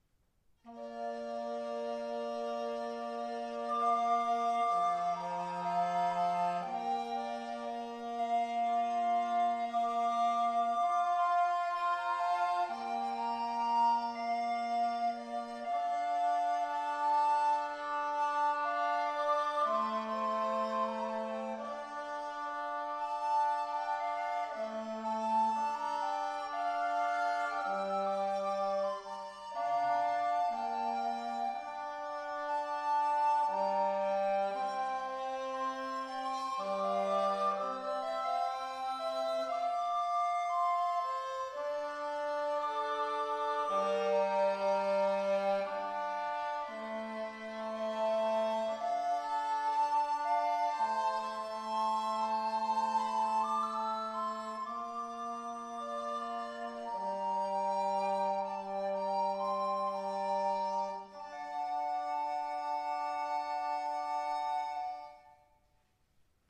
Die Hörbeispiele sind Live-Mitschnitte aus unterschiedlichen
Sopranblockflöte, Altblockflöte, Fidel, Nicolopommer